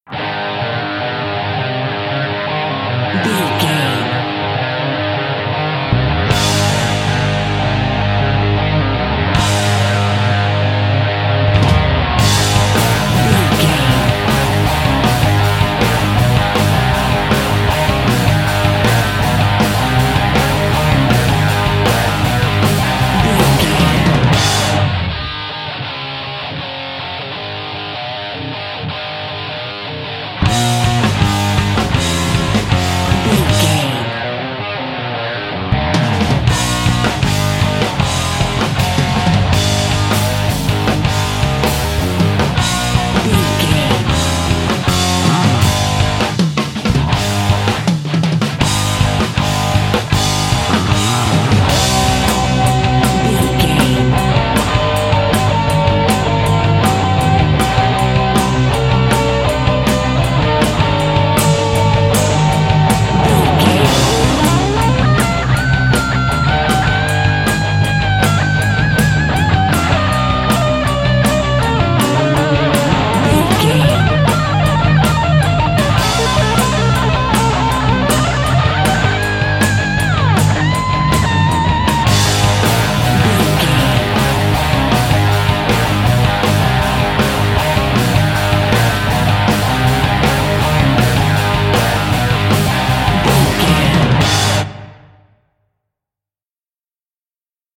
Aeolian/Minor
A♭
Fast
drums
electric guitar
hard rock
lead guitar
bass
aggressive
energetic
intense
powerful
nu metal
alternative metal